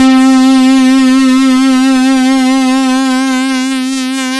Speed Lead (Add Glide!).wav